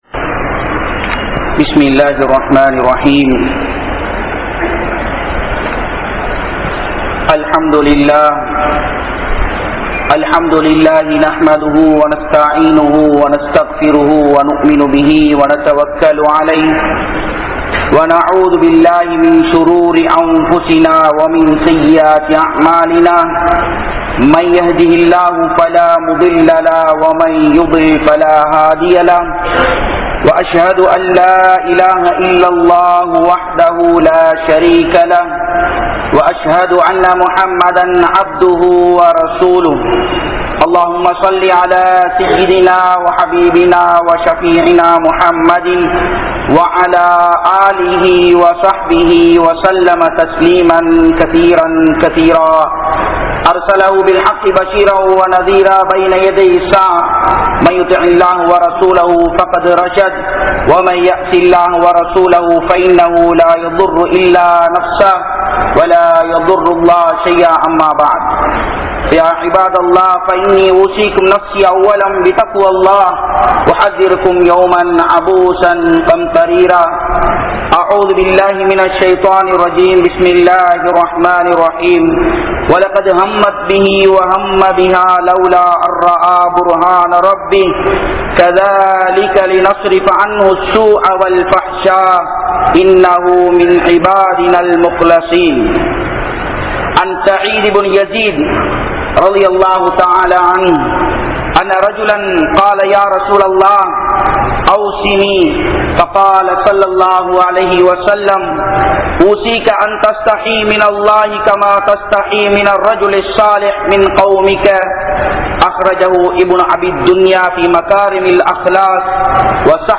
Valentines dayum Kalaachaara Seeralivum (காதலர் தினமும் கலாச்சார சீரழிவும்) | Audio Bayans | All Ceylon Muslim Youth Community | Addalaichenai
Grand Jumua Masjidh